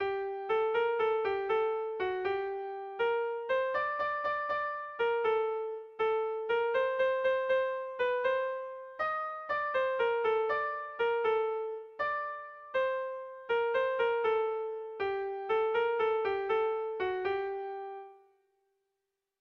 Irrizkoa
Lau puntuko berdina, 8 silabaz
ABDEA